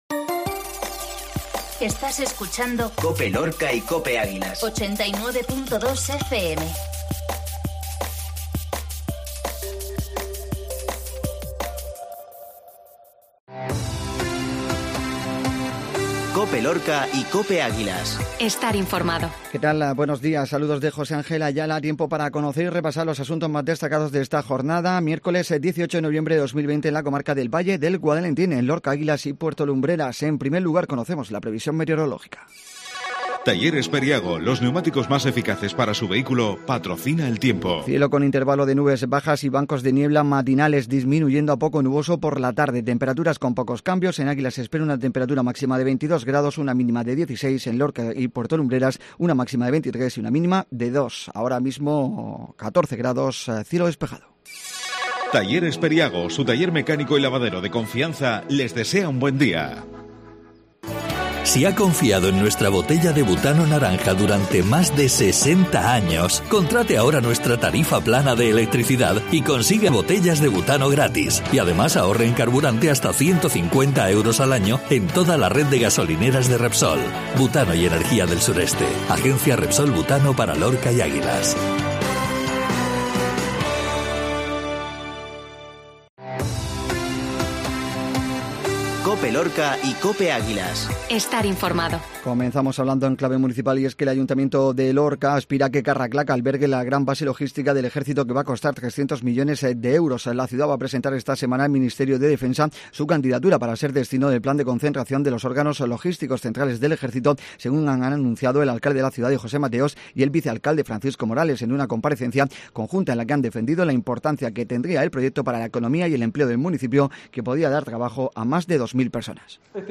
INFORMATIVO MATINAL COPE LORCA